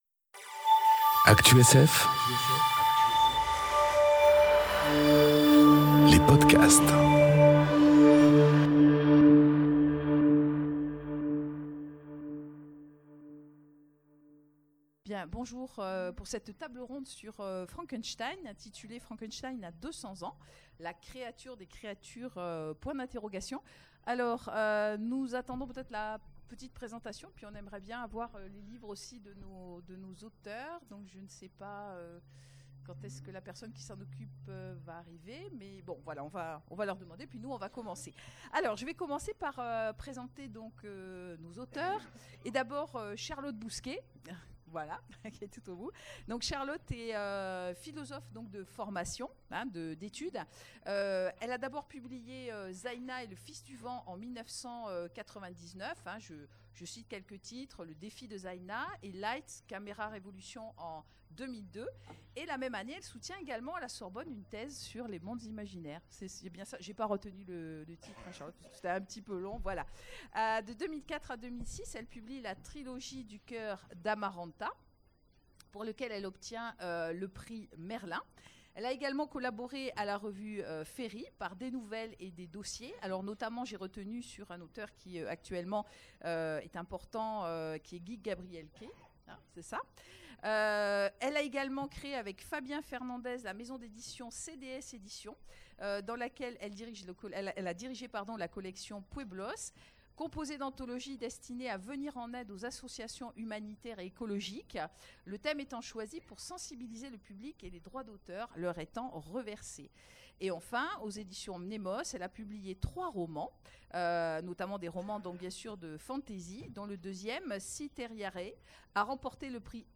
Conférence Frankenstein a deux cents ans... enregistrée aux Imaginales 2018